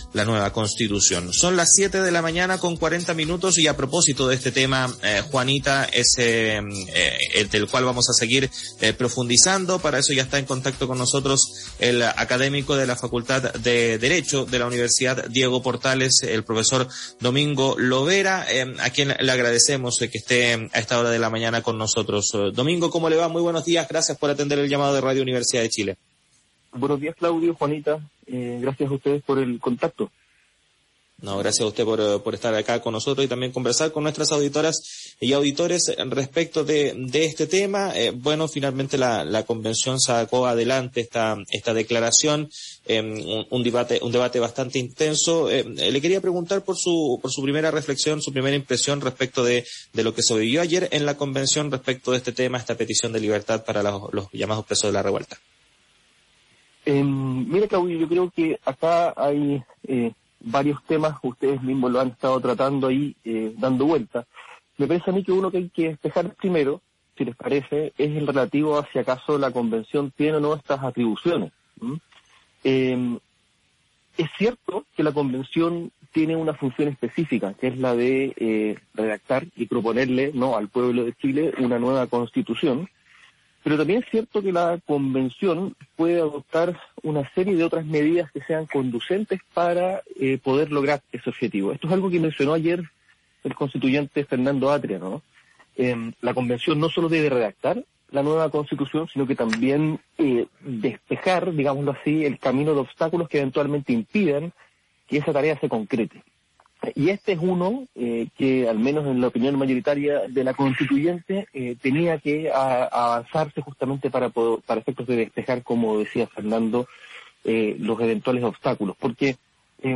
Radio UChile | Entrevista